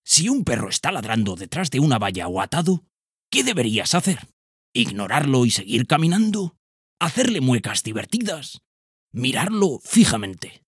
TEST PERROS DESCONOCIDOS-Narrador-06.ogg